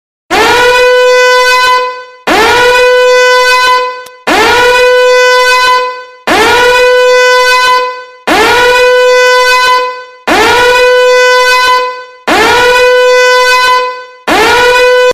Danger Alarm Sound Effect Meme